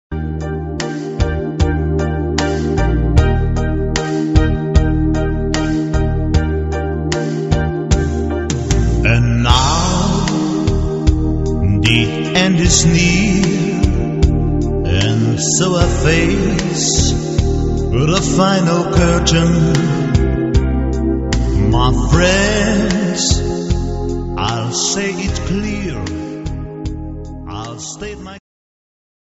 Party-Music-Band